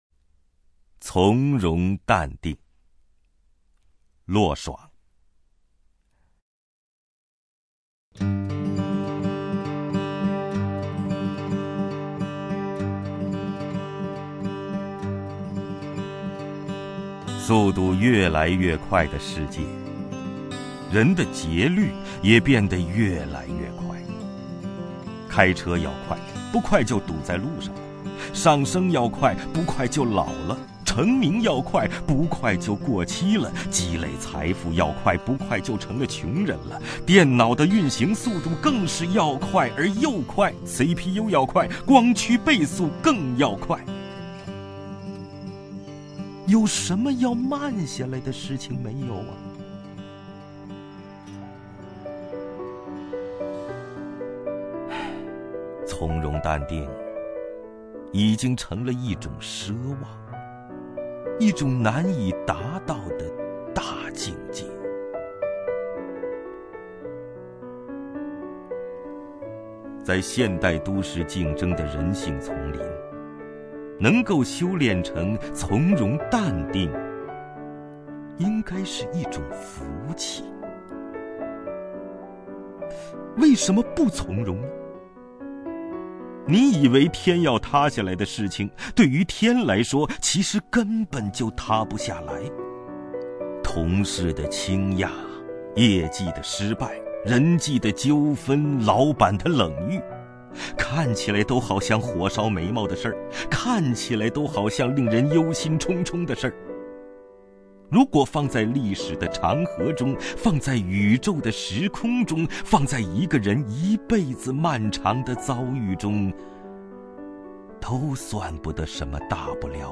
王凯朗诵：《从容淡定》(骆爽) 骆爽 名家朗诵欣赏王凯 语文PLUS